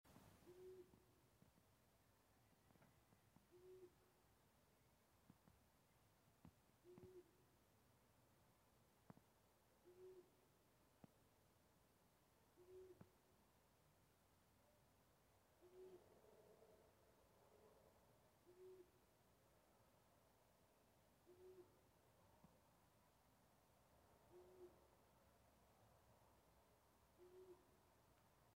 Distant Owl Hooting Bouton sonore